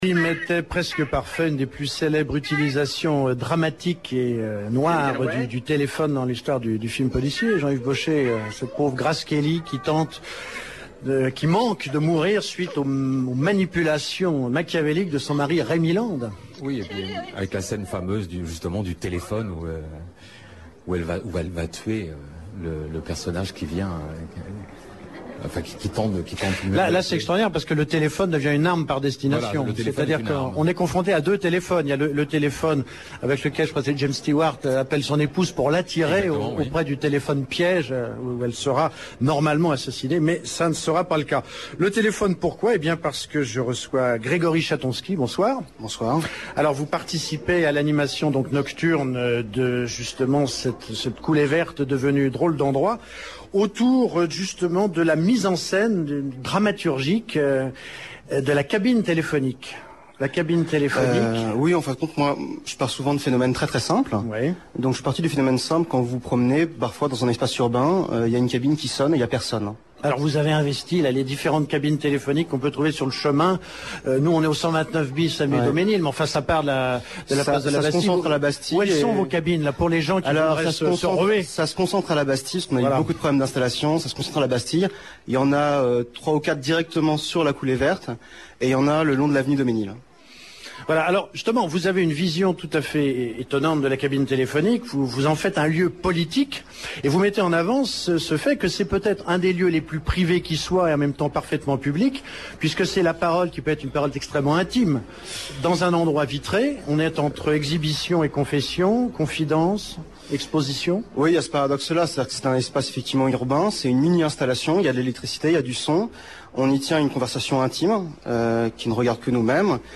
A l’occasion de Nuit blanche 2005, « Mauvais genres » s’expatrie, quittant les studios de Radio France pour une rotonde vitrée, 127 avenue Daumesnil, au cœur de la coulée verte.
Christophe Girard, adjoint au Maire de Paris, chargé de la culture.